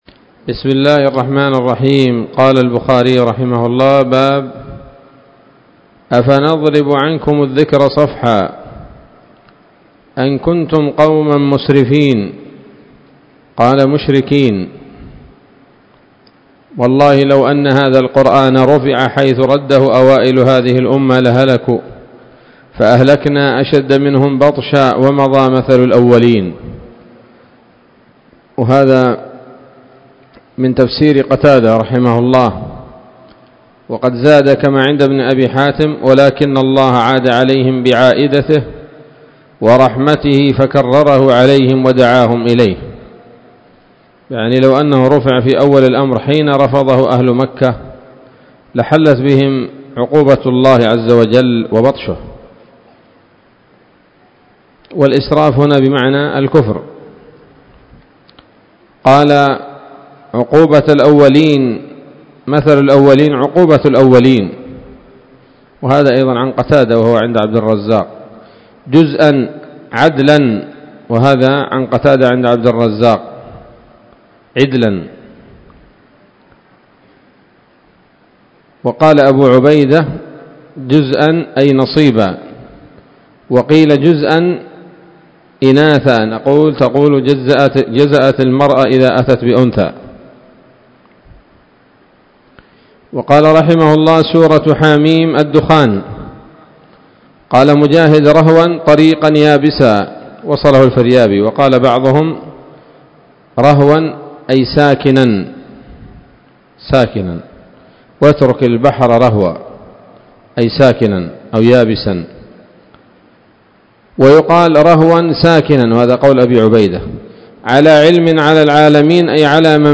الدرس السادس والعشرون بعد المائتين من كتاب التفسير من صحيح الإمام البخاري